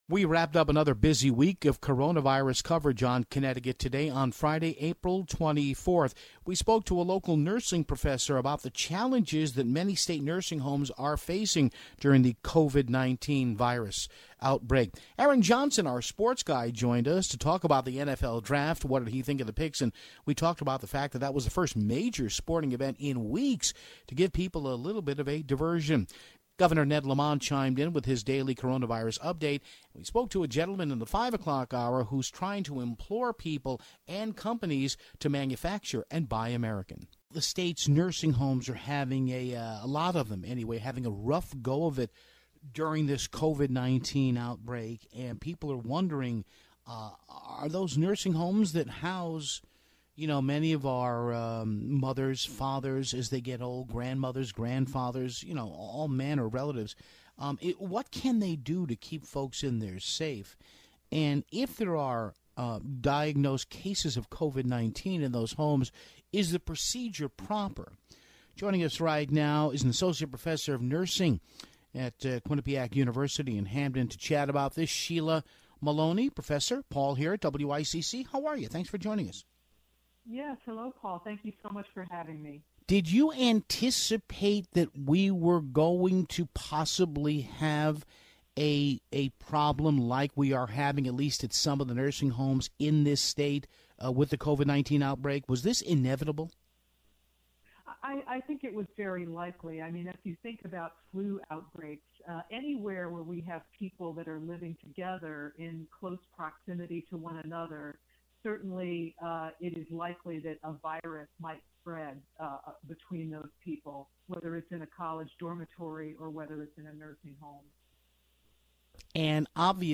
We of course aired a small portion of Governor Ned Lamont's daily press update.